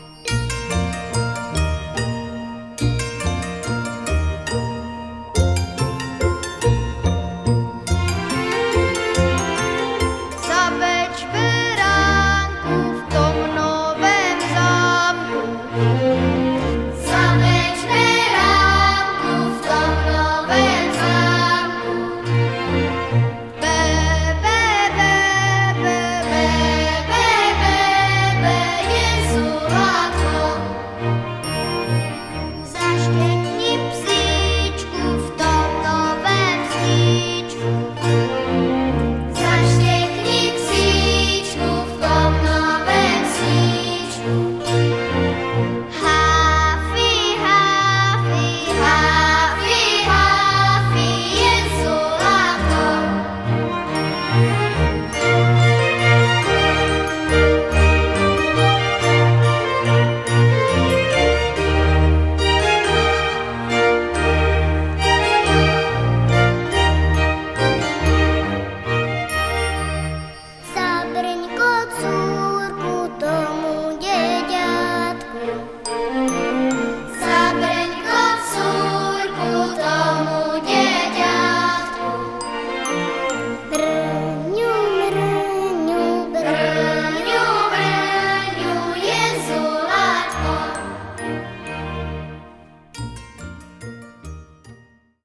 lidová
Zpěv